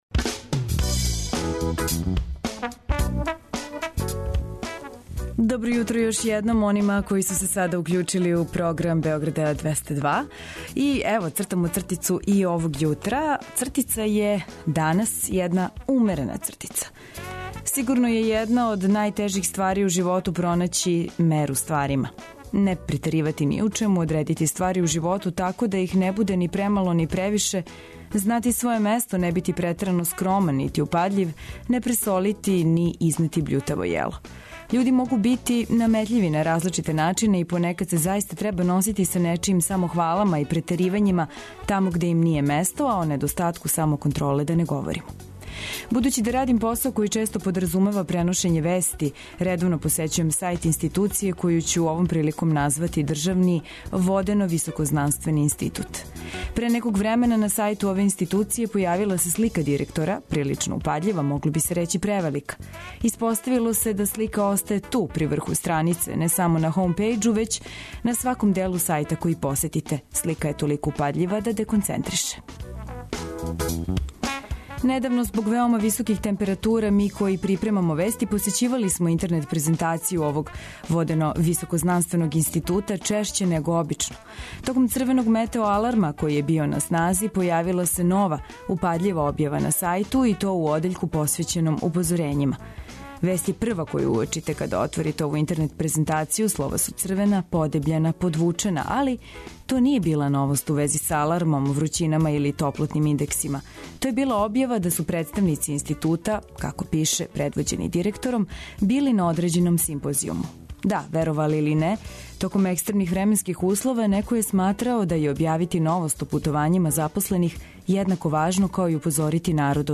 Да Устанак не би био Одустанак, припремамо за вас музику за размрдавање, нове вести, нудимо идеје за излазак, разлог за осмех и информације које је добро чути ујутру, пре кретања од куће.